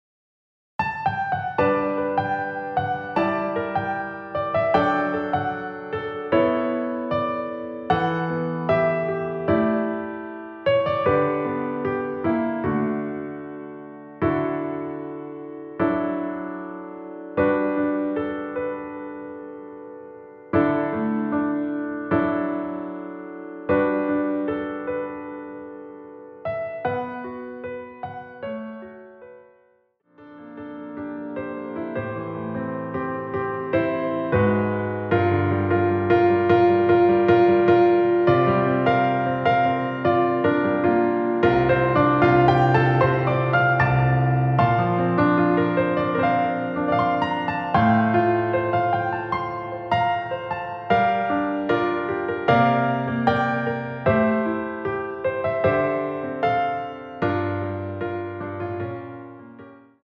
원키에서(+6)올린 MR입니다.
여성분이 부르실수 있는키로 제작 하였습니다.(미리듣기 참조)
앞부분30초, 뒷부분30초씩 편집해서 올려 드리고 있습니다.